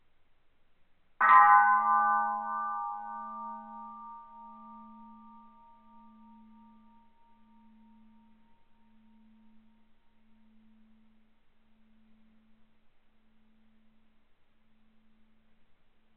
Bell 010
bell bing brass ding sound effect free sound royalty free Sound Effects